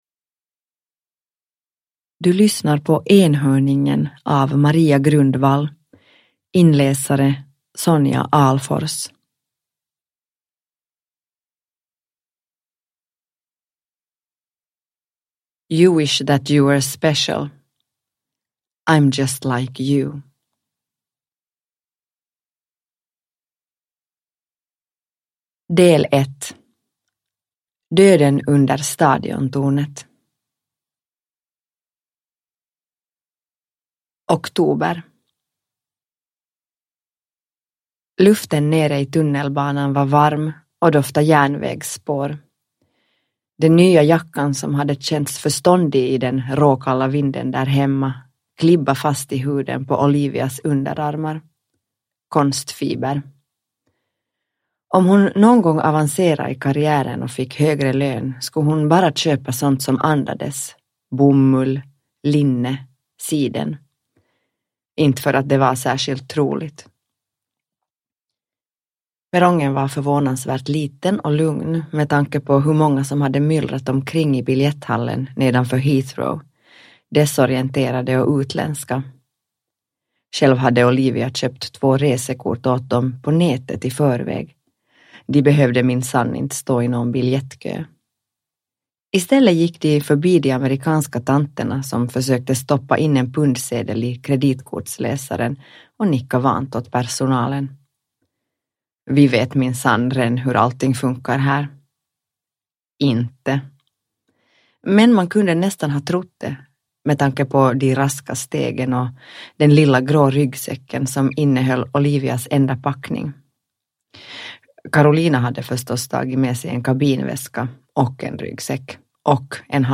Enhörningen – Ljudbok – Laddas ner